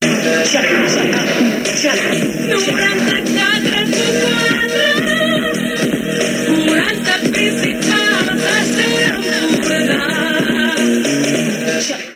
Indicatiu del programa en català